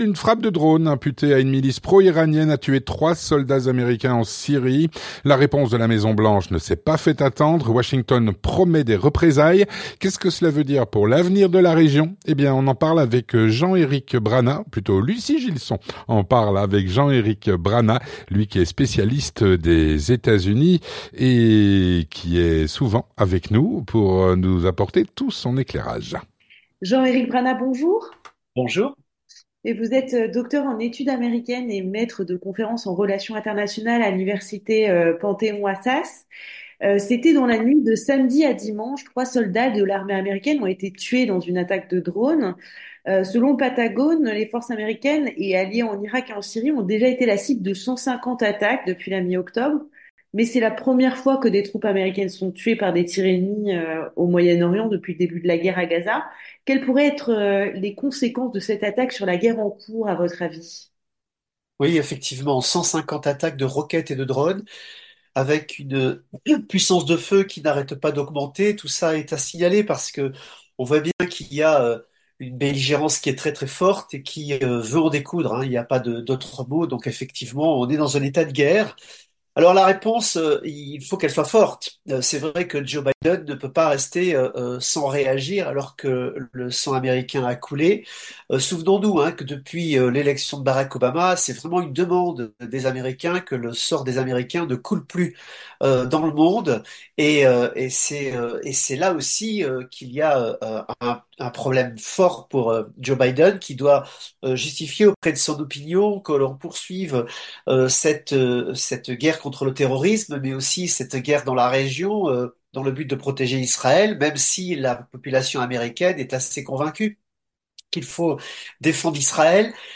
L'entretien du 18H - Une frappe de drone, imputée à une milice pro-iranienne, a tué 3 soldats américains en Jordanie.